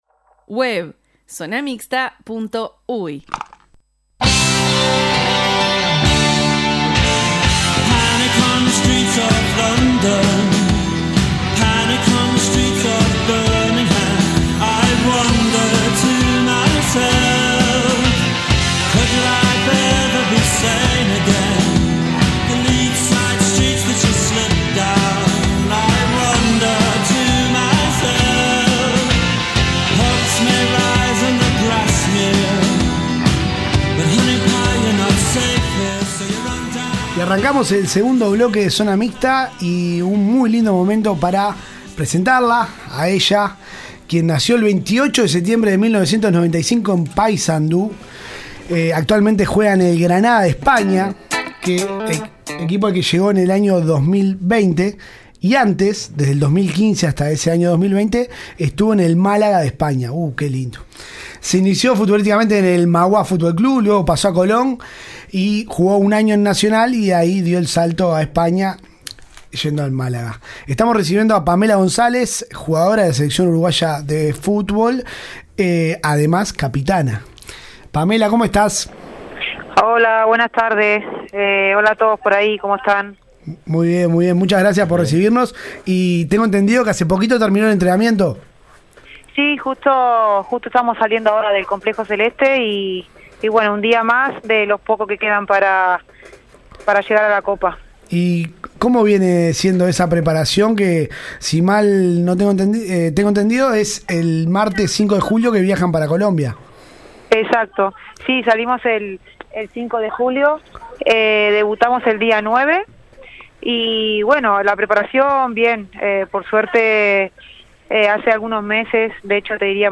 Zona Mixta: entrevista